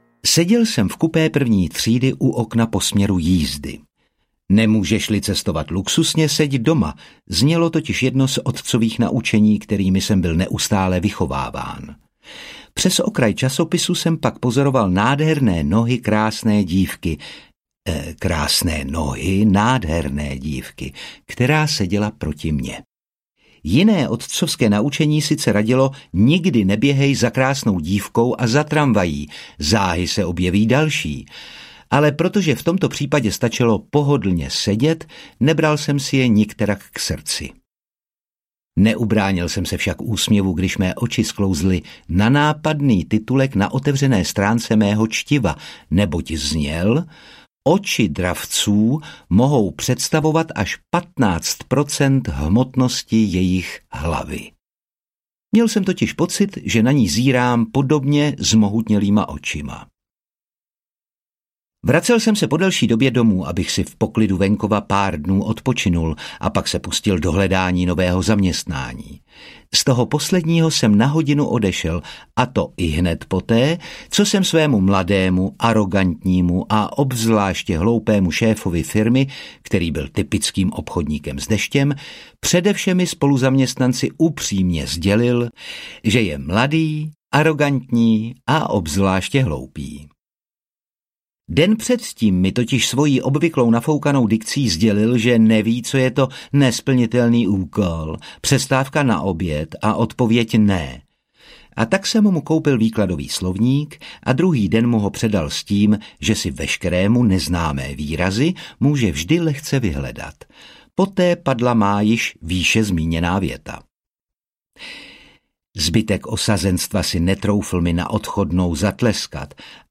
Ona, oni a já audiokniha
Ukázka z knihy
• InterpretOtakar Brousek ml.